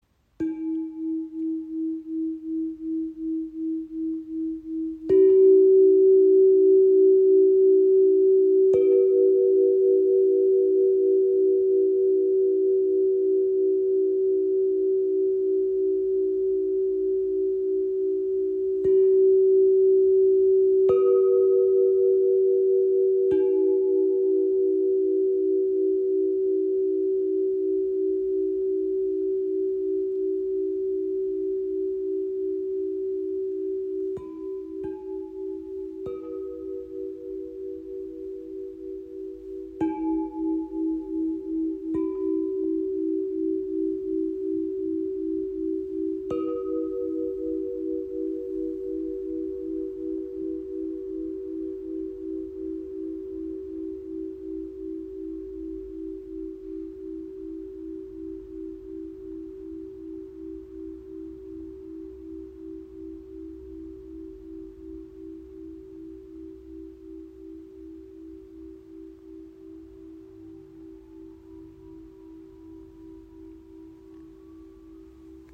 • Icon Drei harmonische Töne für weiche und zugleich kraftvolle Klangteppiche
Chordium L50 Klangröhren E minor | E4-G4-B in 432 Hz
E Moll (E G B): mitfühlend und zart, unterstützt beim Lösen von Schmerz oder angestauten Gefühlen.